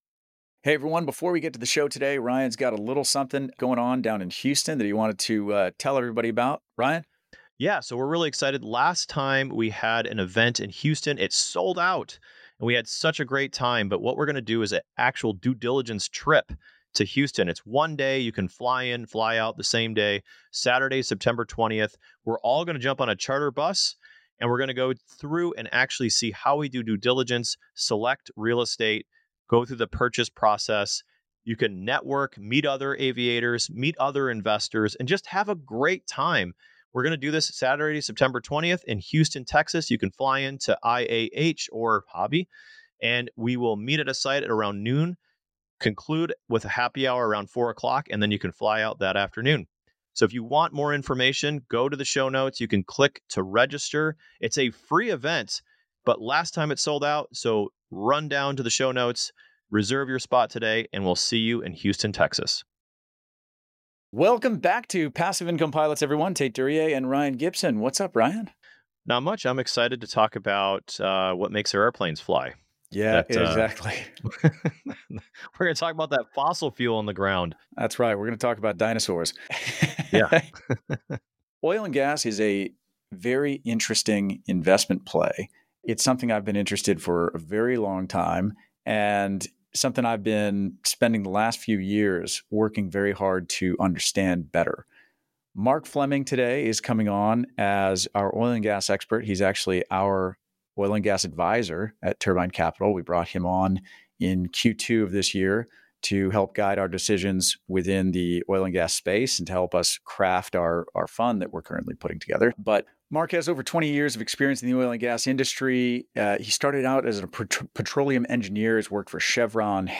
1 Ken Rogoff, Professor of Economics, Harvard and Former Chief Economist, IMF 53:40 Play Pause 2h ago 53:40 Play Pause Play later Play later Lists Like Liked 53:40 On this episode of the Alpha Exchange, I had the pleasure of reconnecting with Ken Rogoff, Professor of Economics at Harvard and former Chief Economist at the IMF.